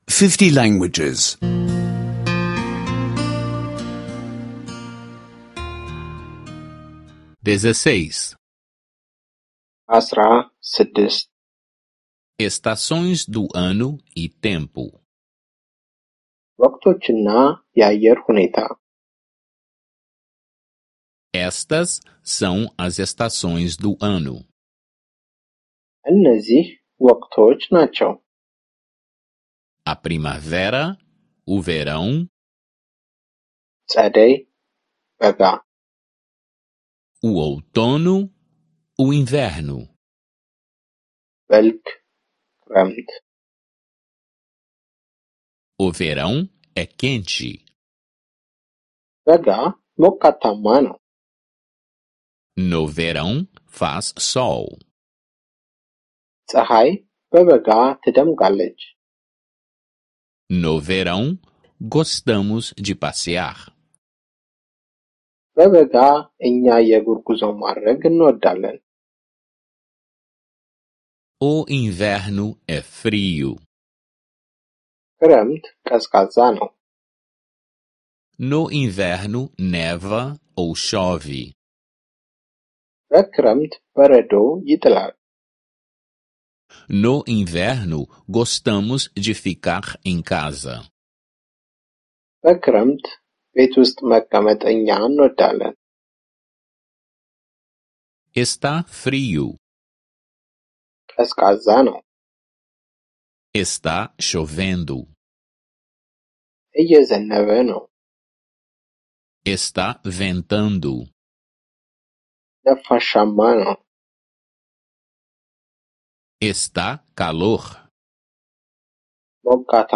Aulas de amárico em áudio — escute online